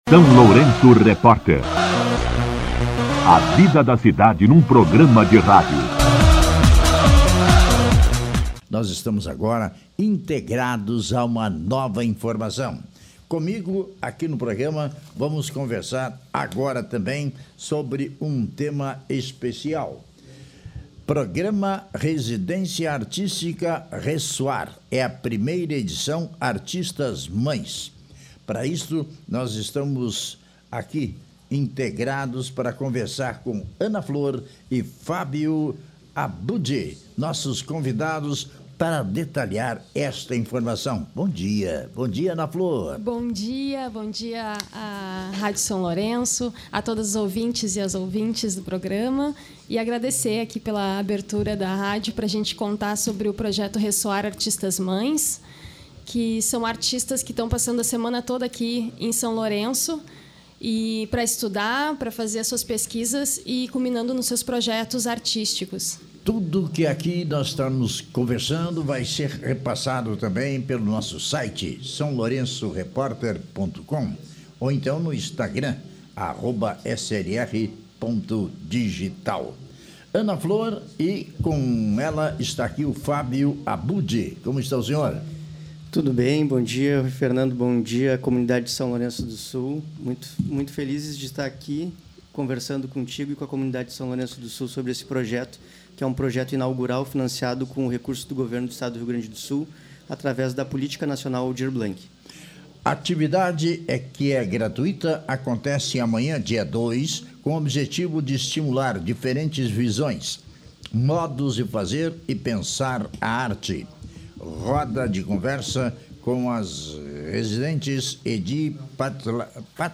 Entrevista com a comissão organizadora